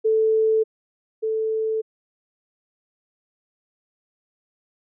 2つの音を聴いて後者が前者に比較してどうかという一対比較と、1つの音を聴いて例えばその周波数を当てるなどの絶対判定の、2種類の訓練があります。
音源提示例
例題音源を添付しておりますので、こちらから試聴できます(正解：小)。